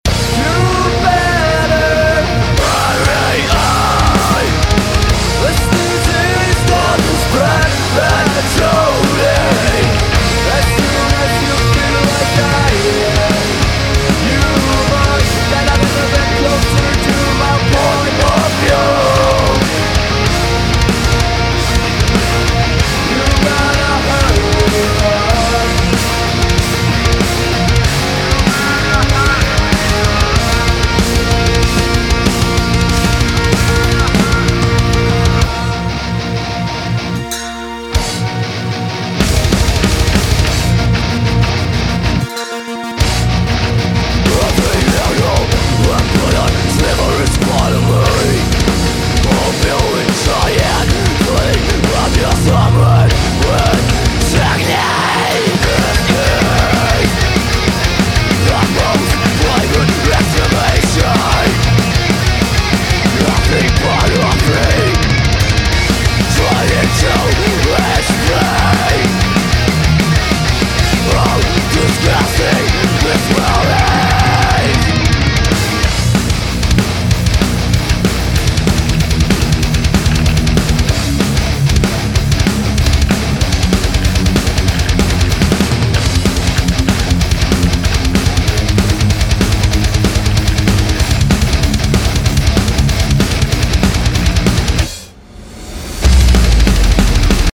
EDIT: just dropped it in this very old and VERY rough mix to show you how it's sitting.
I was aiming for an IF kinda vibe (as you can tell), so I want the snare to sound very round, fat and natural with just enough "pop" to pop through the heavy guitars.
in the mix
again, don't judge the mix or anything, this is an old recording and I didn't really mix it.
The snare is without any post processing (other than a touch of reverb), this is the raw sample replacing the original snare 100%